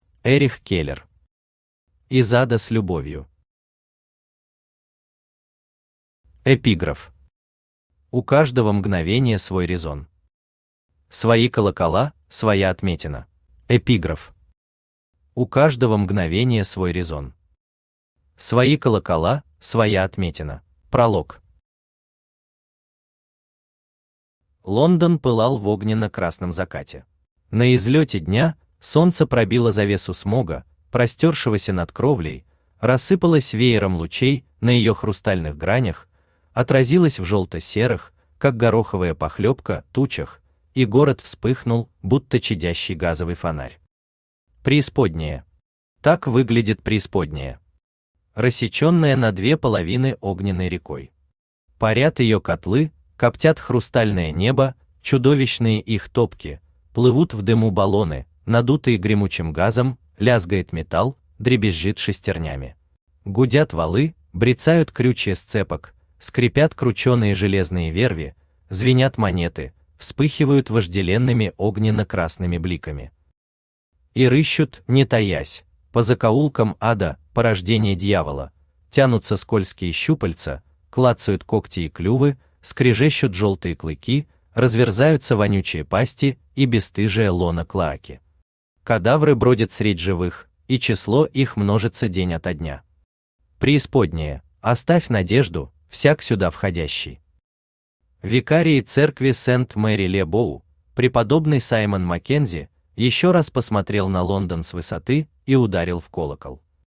Aудиокнига Из ада с любовью Автор Эрих Келлер Читает аудиокнигу Авточтец ЛитРес.